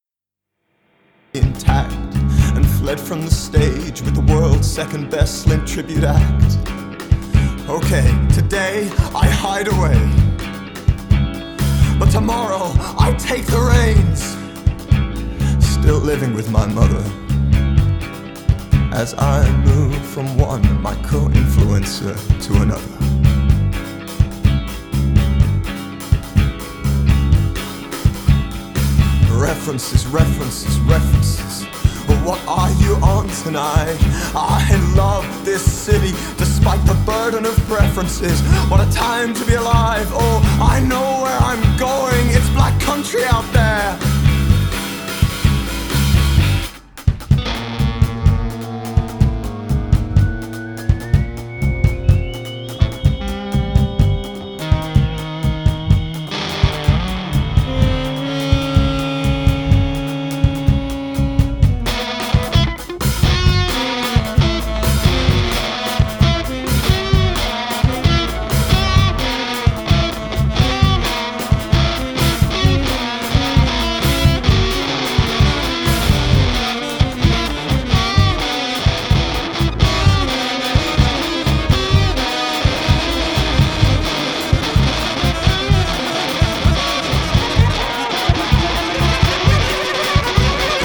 Nada assim tão rock, tão crente no rock.